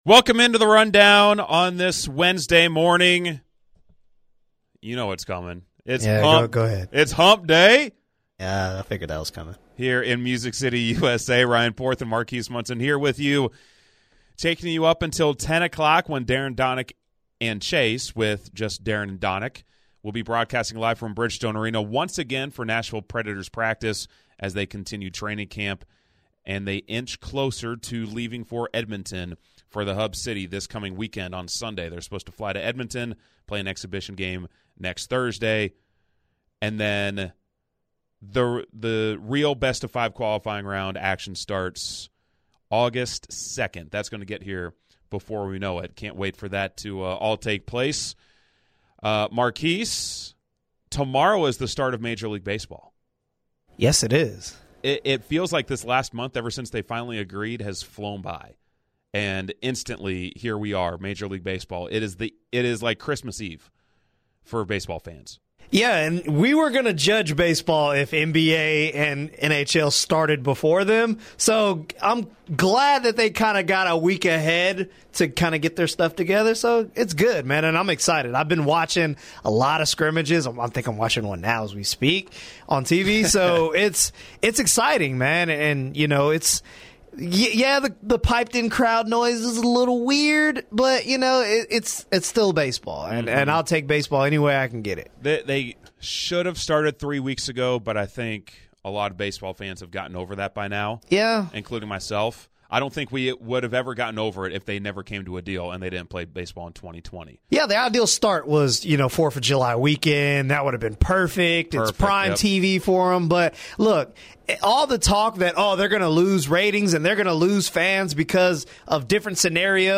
live from Bridgestone Arena